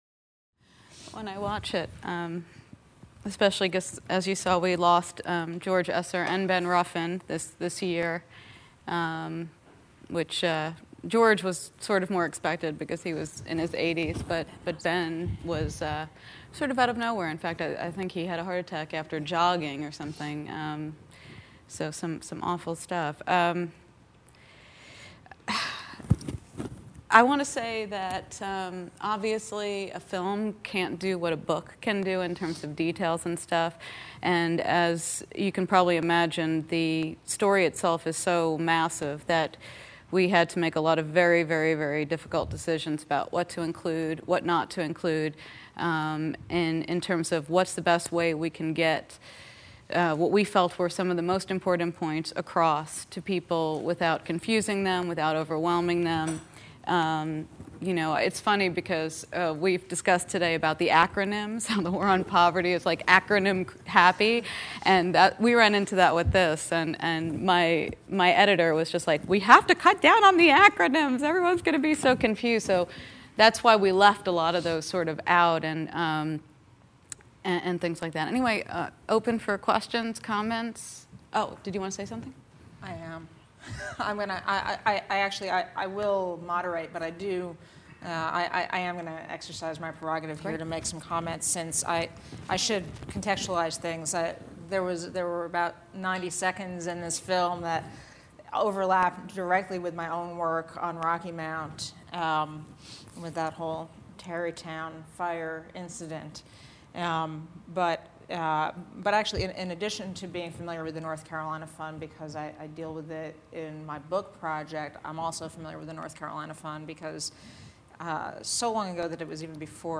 Film Screening & Discussion | Miller Center
6:00 pm Miller Center Forum Room "Change Comes Knocking